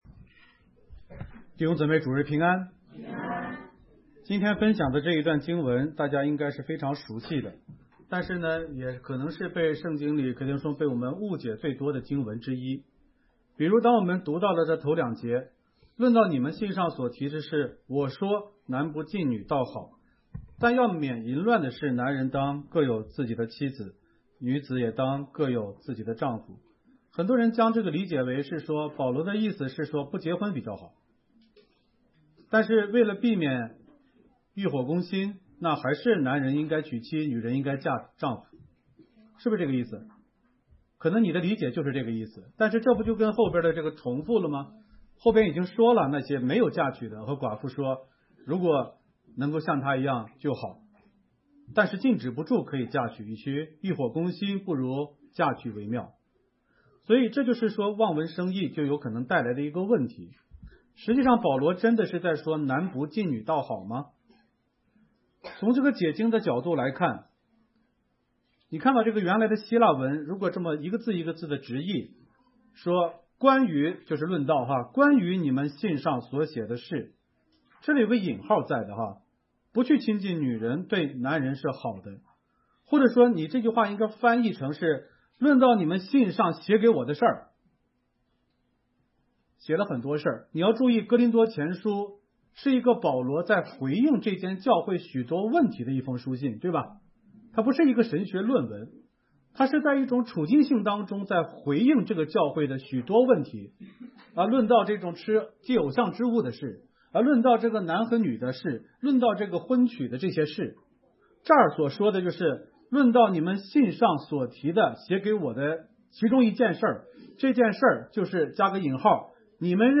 首页 讲章 正文 男不近女倒好？